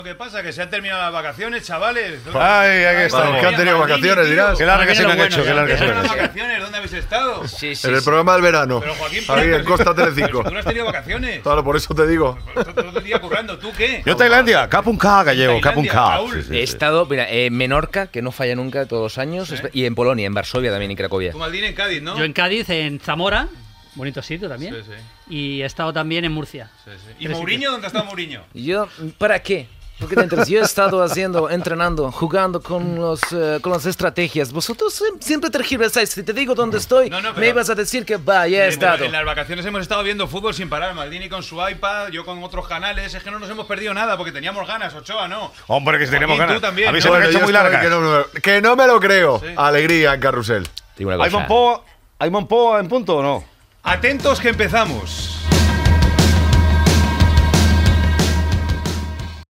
L'equip diu on ha passat les vacances. També hi intervé Raúl Pérez imitant a l'entrenador de futbol José Mourinho
Esportiu